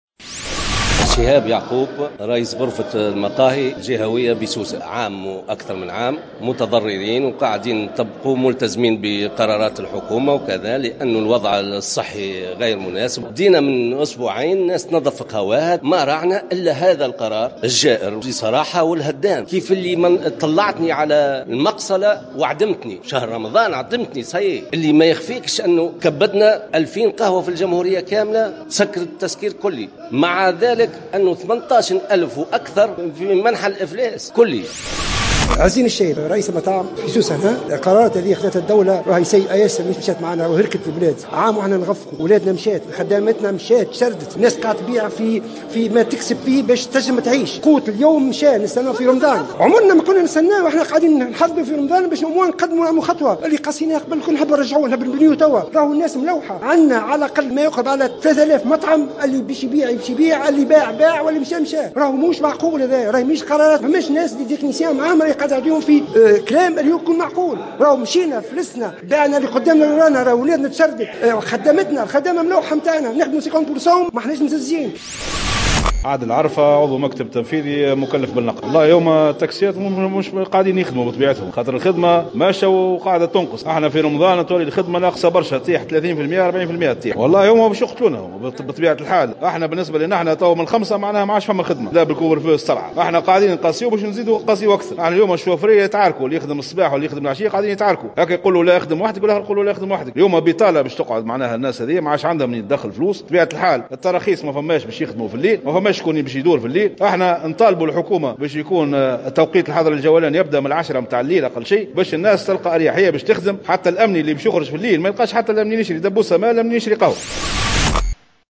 خلال تحرّك احتجاجي